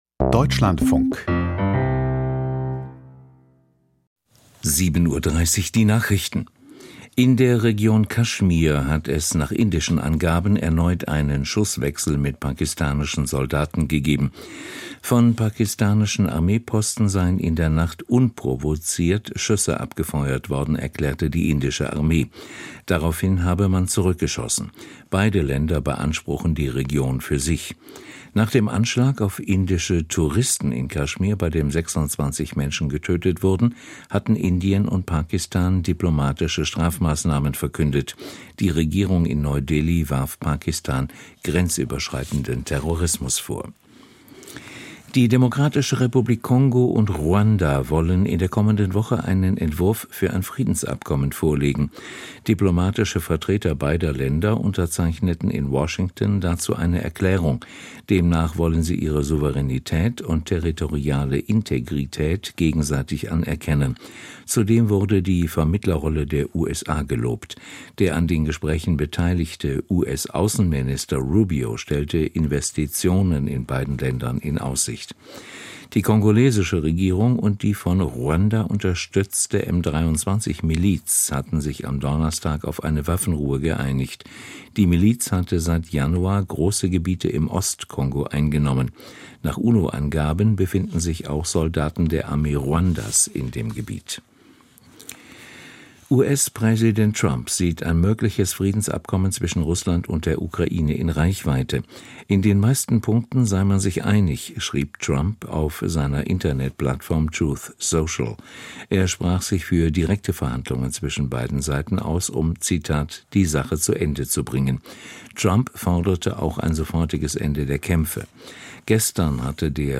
Die Deutschlandfunk-Nachrichten vom 26.04.2025, 07:30 Uhr